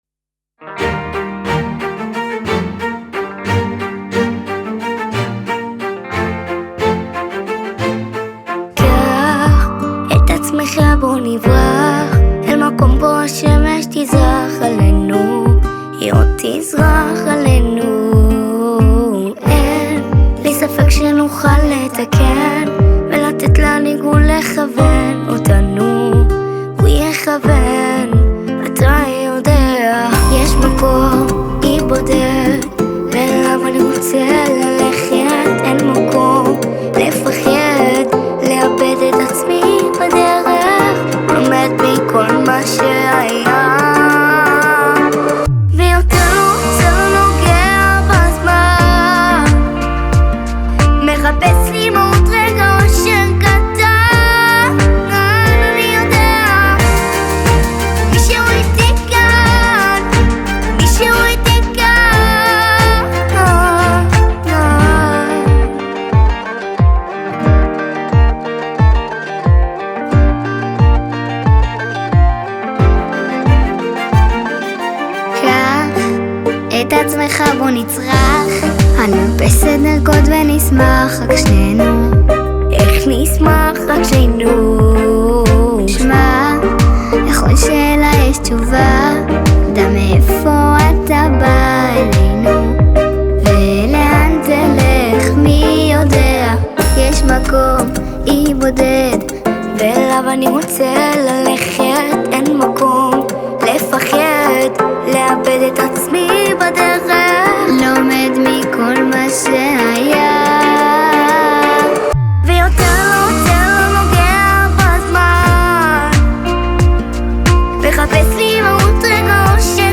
הקלטת קולות : אולפני voice studio